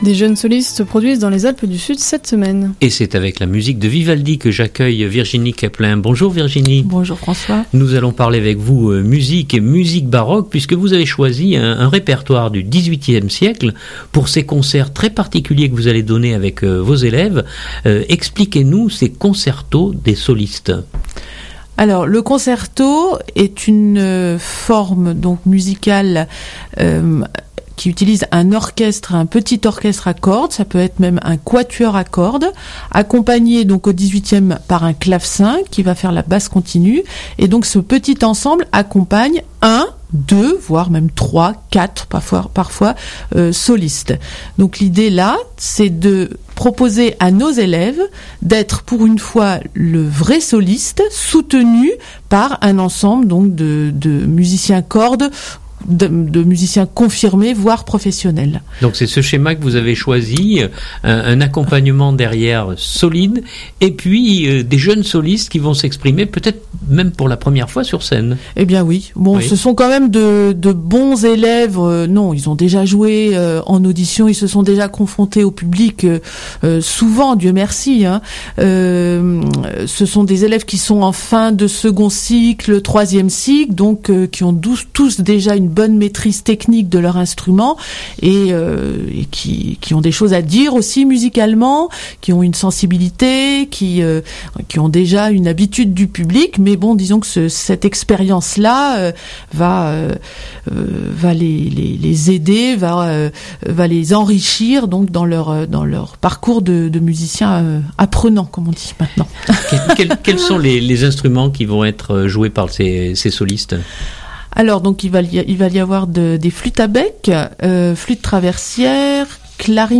Elle est notre invitée.